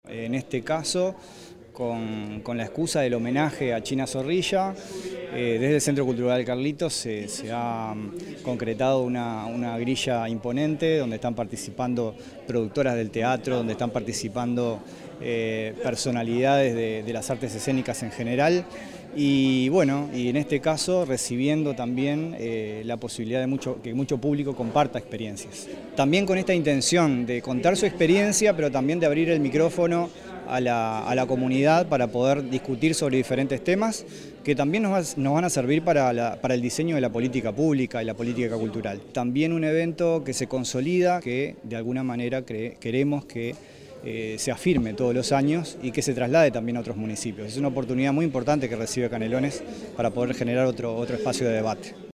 sergio_machin-_director_general_de_cultura_0.mp3